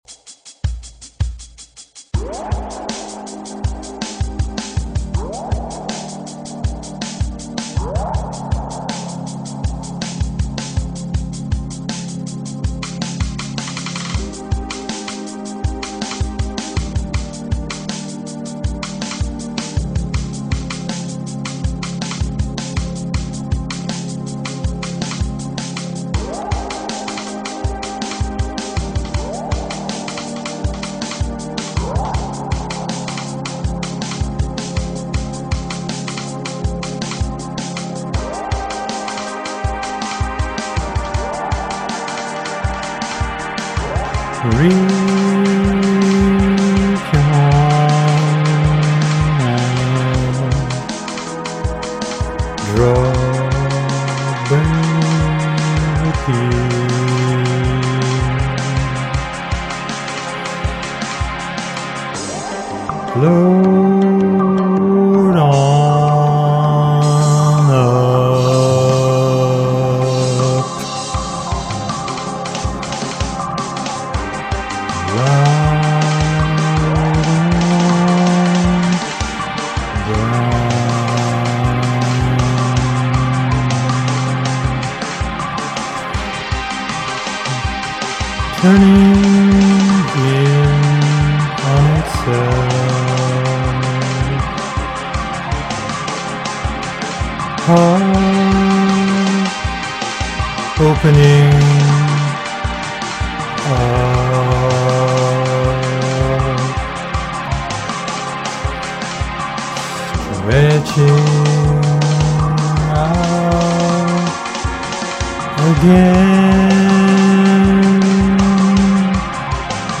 Spoken Word with Ambient Chill music,